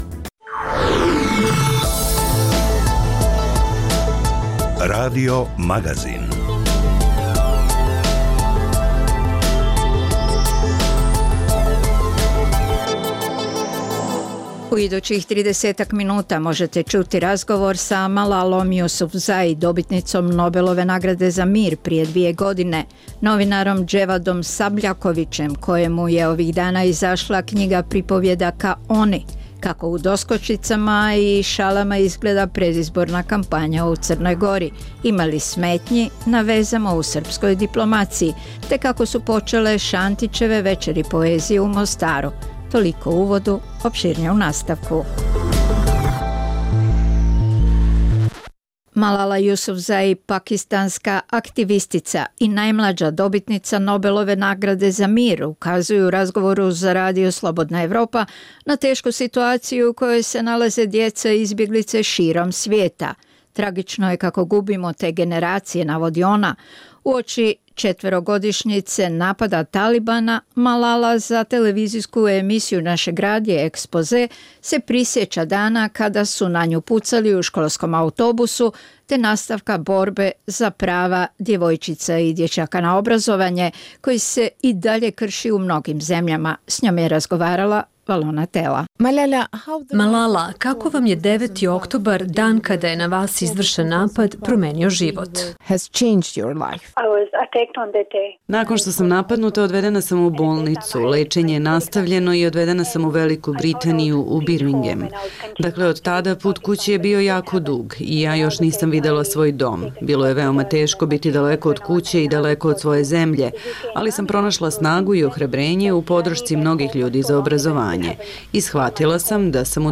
- Možete ćuti razgovor s Malalom Yusufzai, dobitnicom Nobelove nagrade za mir prije dvije godine